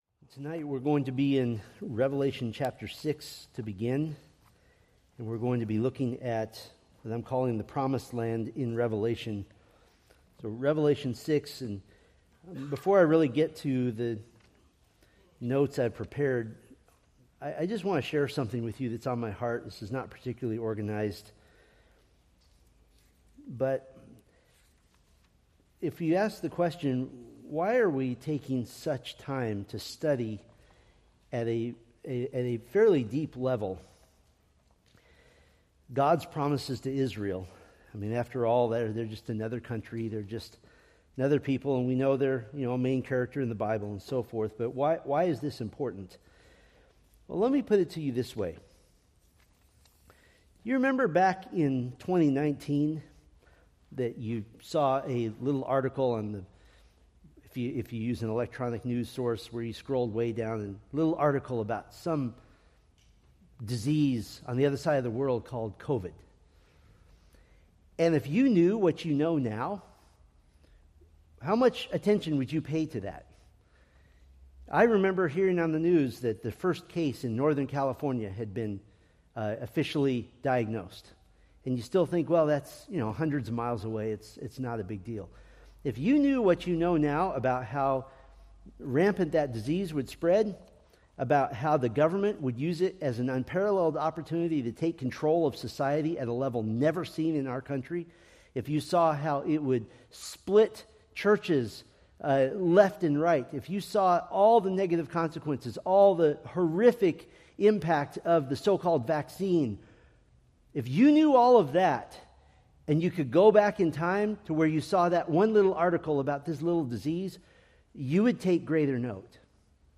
From the Millennium: The Promised Land sermon series.